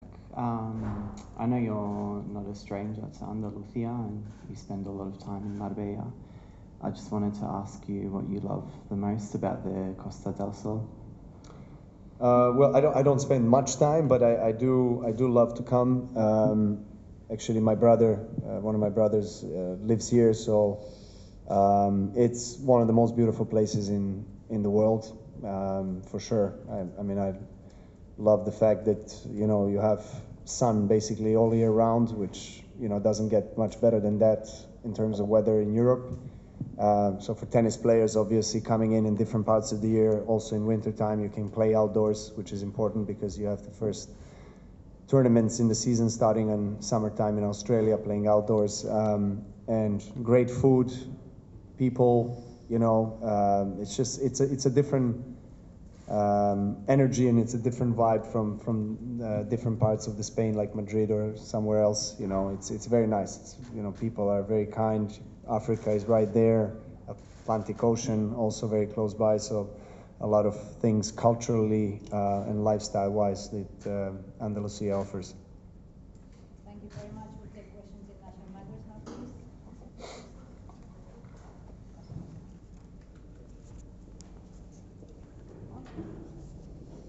Djokovic en una entrevista en la Copa Davis.